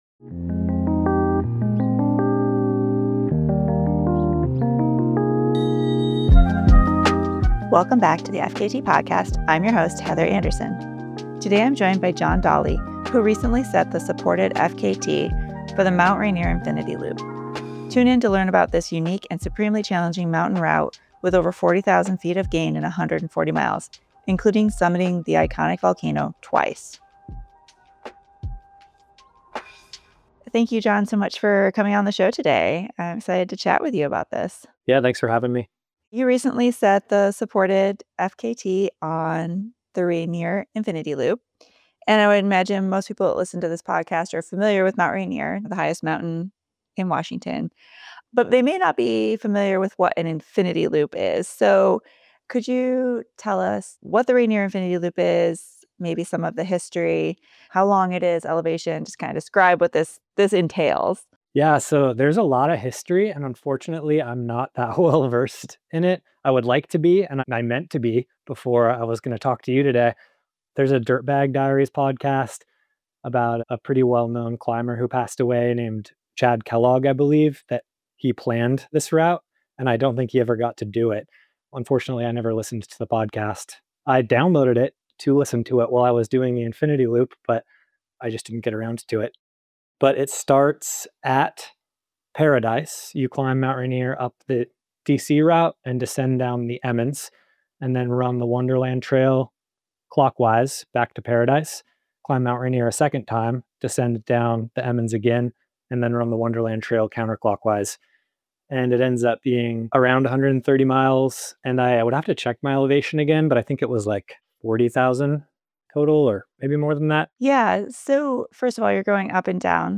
Log in or register to post comments Category Person-Person What does the infinity symbol have to do with FKTs?